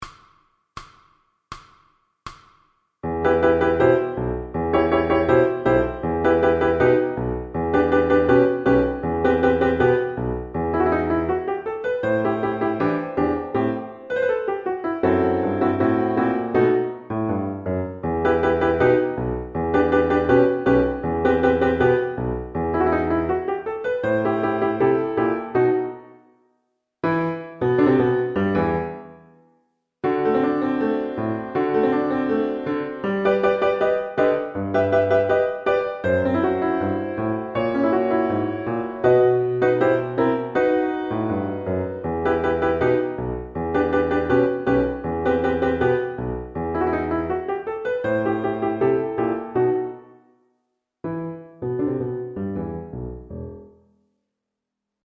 recorded piano accompaniments
Exam Speed